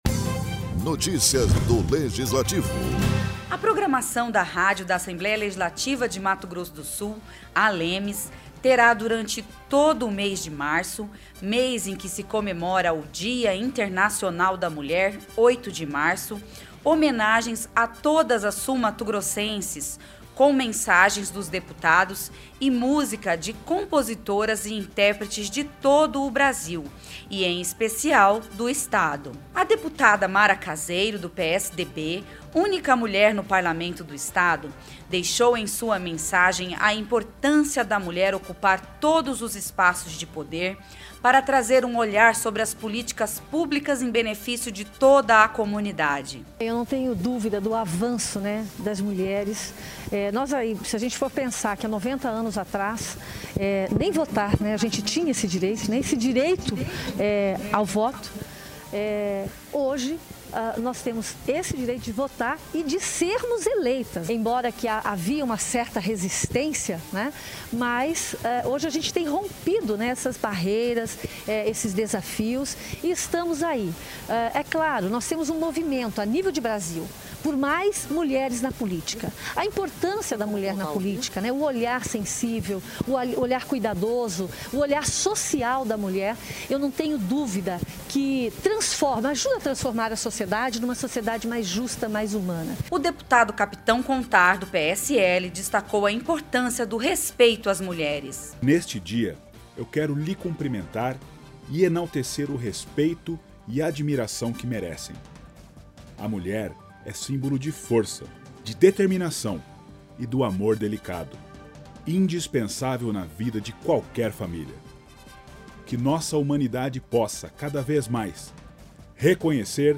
Deputados deixaram mensagens para mulheres do estado e compositoras e interpretes regionais fazem parte da programação comemorativa ao dia internacional da mulher.